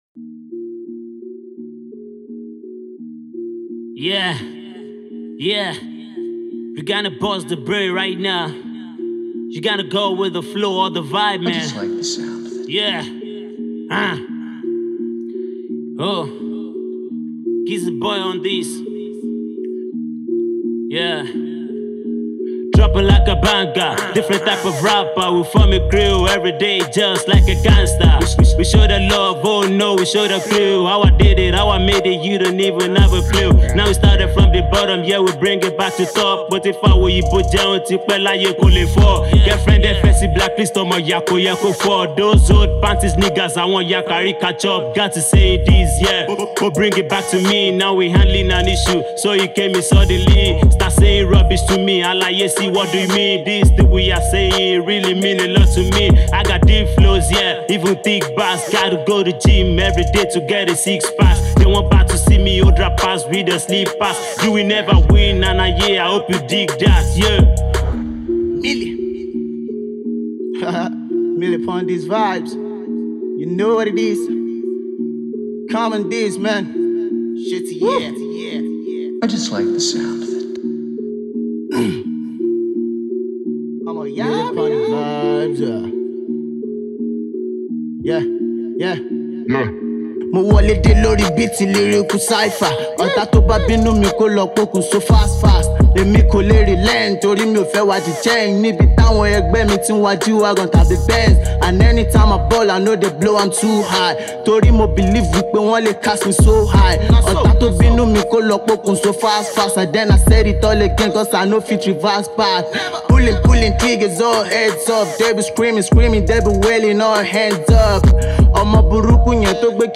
a massive cypher drops from three artists
hood rap battle track